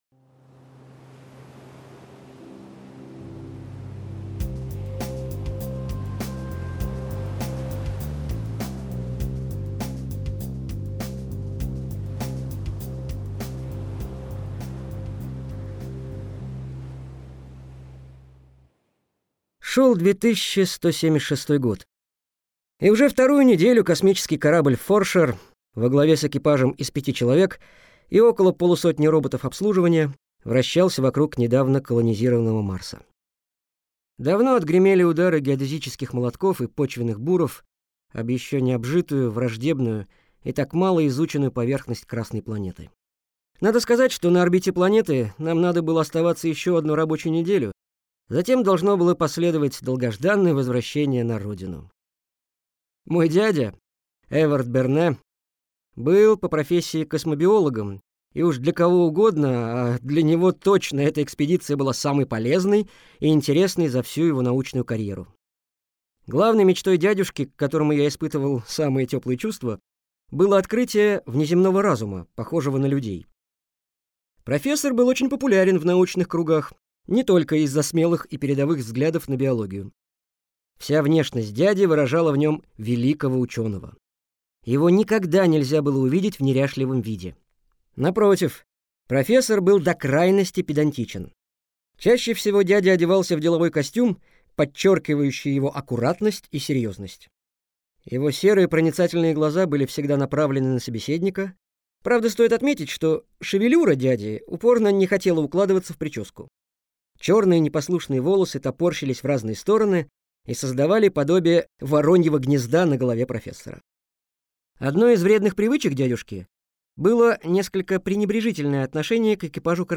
Аудиокнига Планета теней. Часть Первая | Библиотека аудиокниг